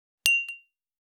284食器をぶつける,ガラスをあてる,皿が当たる音,皿の音,台所音,皿を重ねる,
コップ